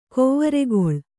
♪ kovvaregoḷ